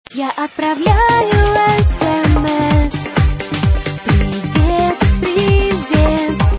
Один из любимых мной сигнал на смс.Качайте и ставте на смс.